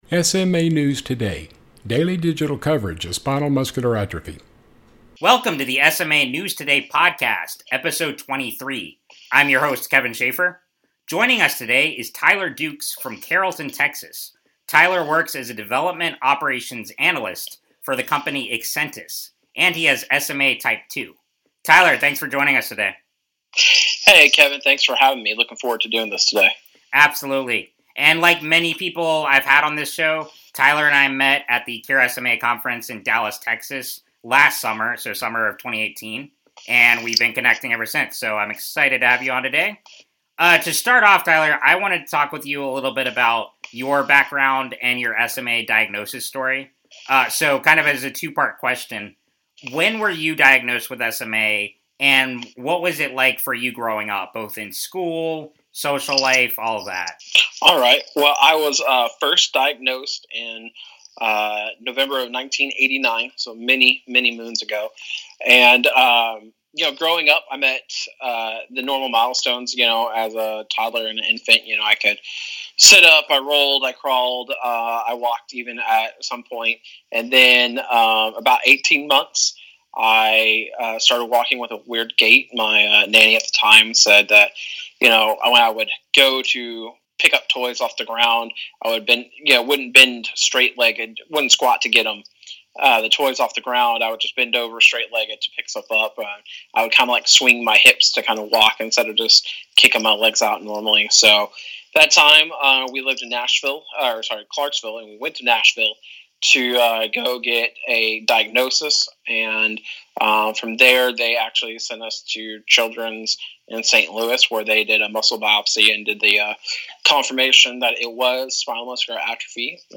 SMA News Today Podcast 23 - Interview